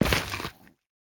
Minecraft Version Minecraft Version snapshot Latest Release | Latest Snapshot snapshot / assets / minecraft / sounds / block / netherrack / step1.ogg Compare With Compare With Latest Release | Latest Snapshot
step1.ogg